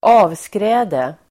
Ladda ner uttalet
Uttal: [²'a:vskrä:de]